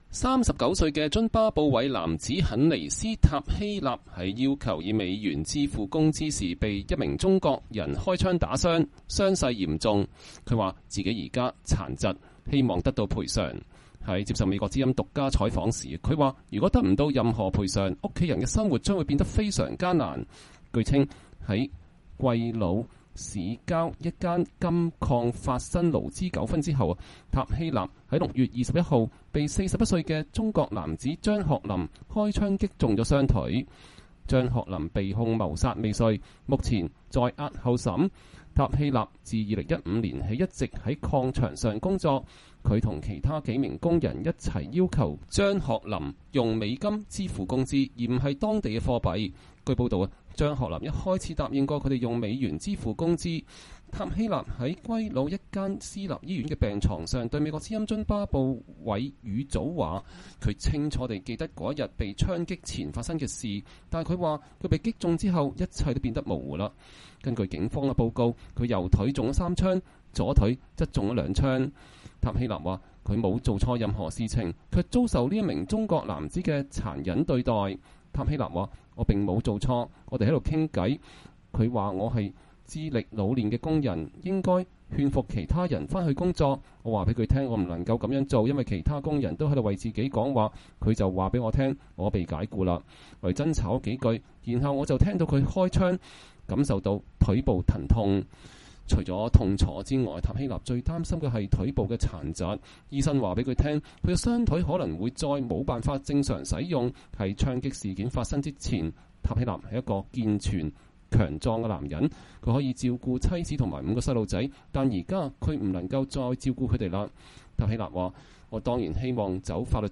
在接受美國之音獨家採訪時，他說，如果得不到任何賠償，家人的生活將變得非常艱難。